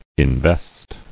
[in·vest]